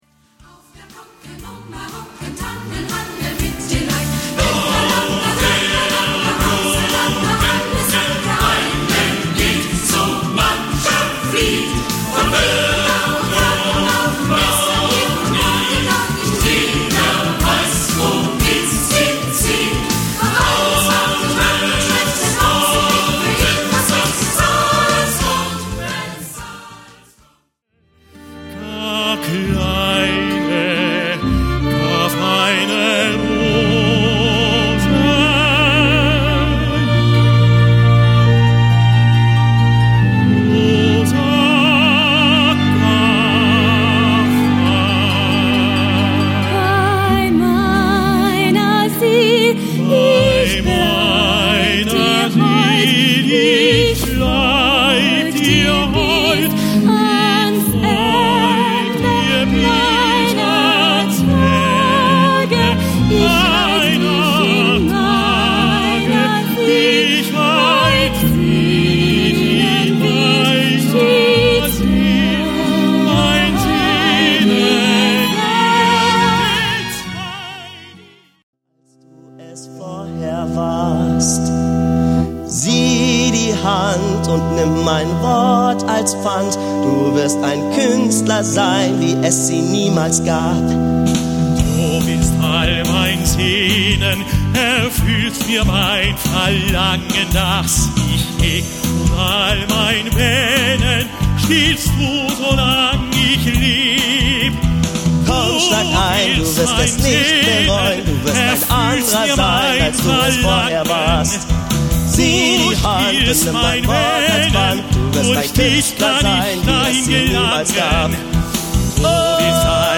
Sopran
Tenor